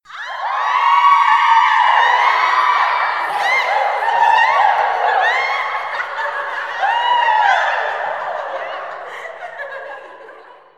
Рингтон Несколько девушек радостно кричат
Звуки на звонок